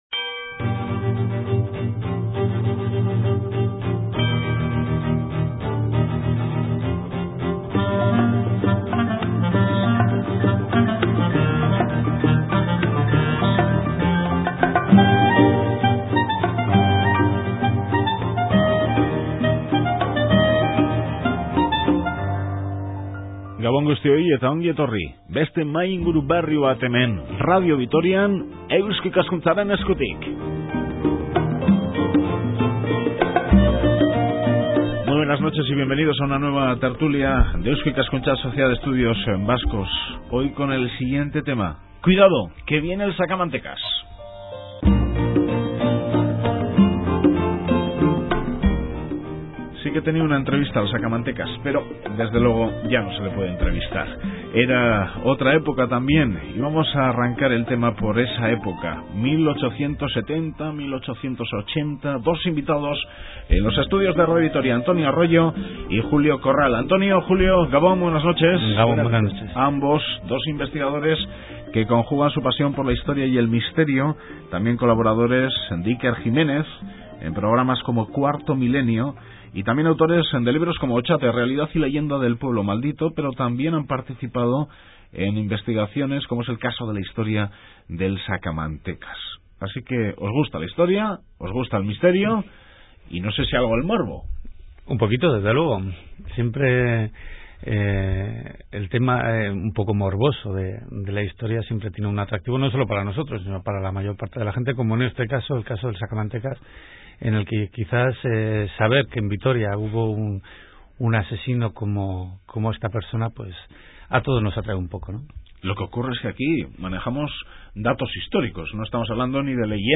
Sus crímenes convulsionaron a la tranquila Vitoria de 1870 y causaron una honda conmoción en toda Europa. La historia del Sacamantecas cambió el sistema judicial español y su protagonista asombra, aún hoy, por los detalles de su compleja personalidad. Para conocer algo más de esta terrible historia del Sacamantecas, de la que Vitoria fue testigo hace unos siglos, no os perdáis esta tertulia.